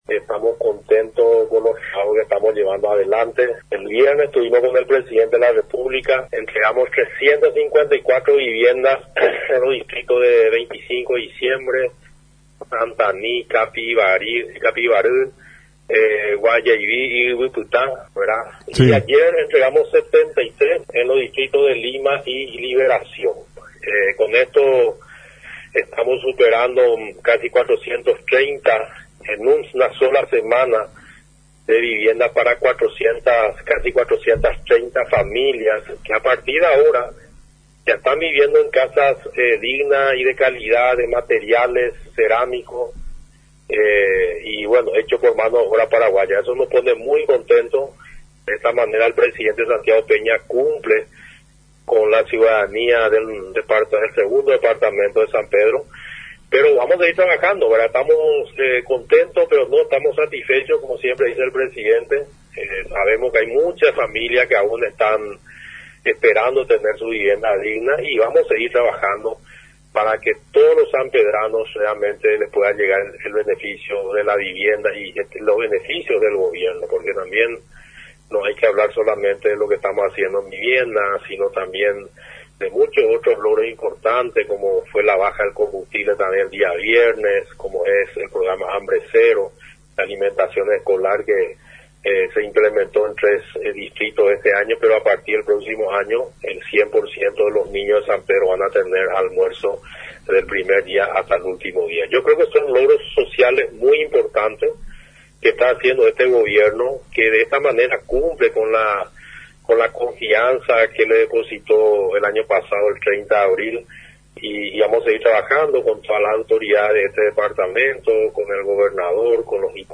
Según indicó a Radio Nacional el titular del MUVH.
EDITADO-10-ING.-JUAN-CARLOS-BARUJA-MINISTRO-DEL-MUVH.mp3